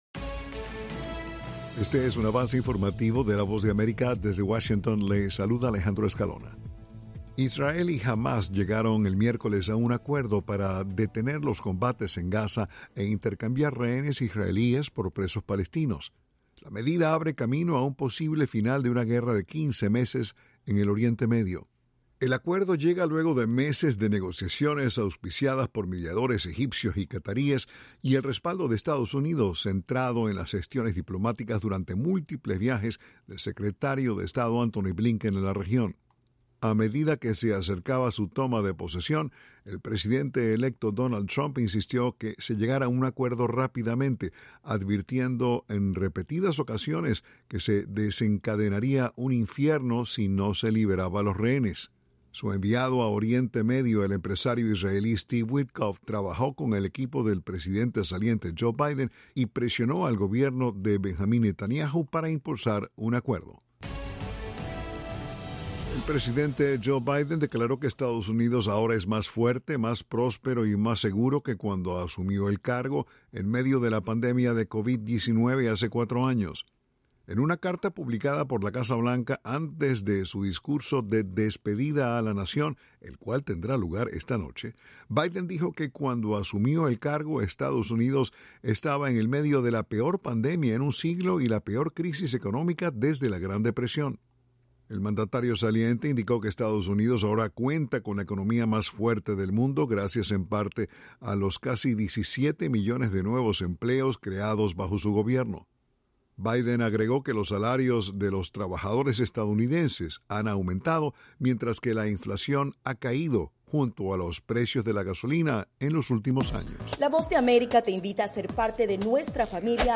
El siguiente es un avance informativo de la Voz de América.